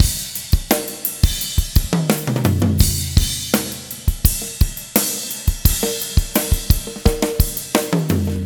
16 rhdrm85fill.wav